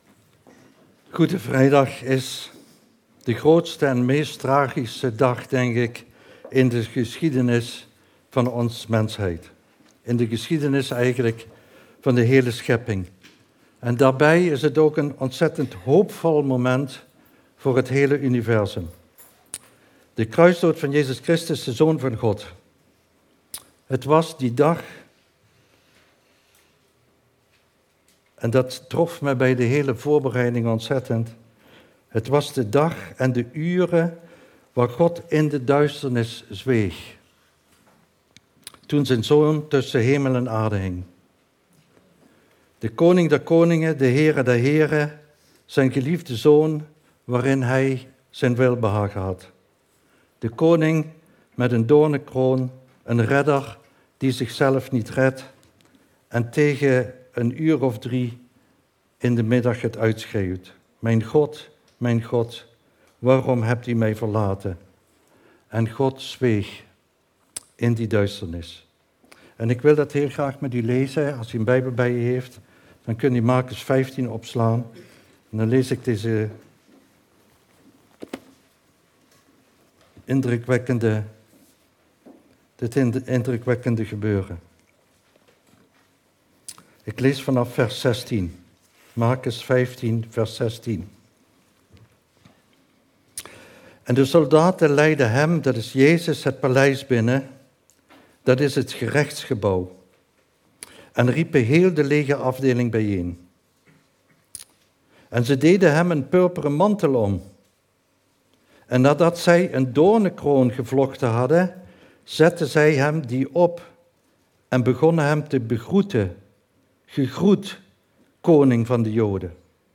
Preken – Christengemeente Midden-Limburg